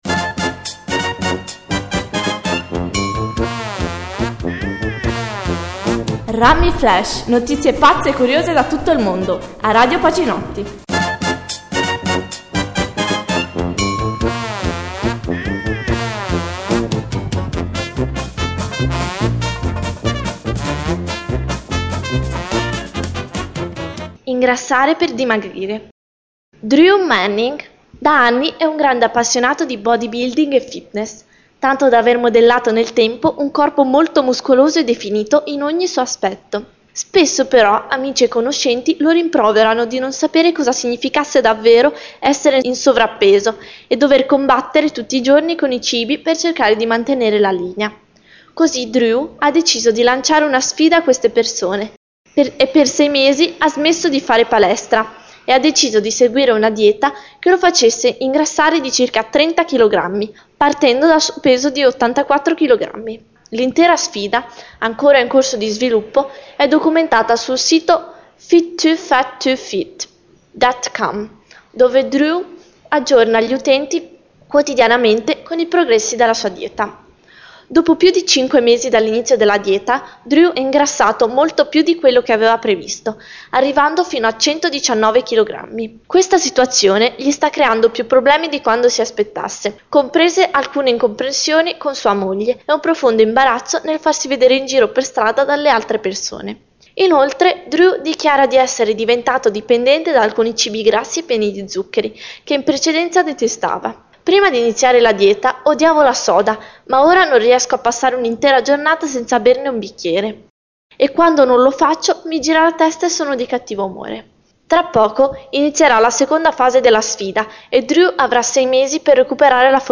Radioweb Pacinotti Notizie curiose e brano di musica con relazione alla notizia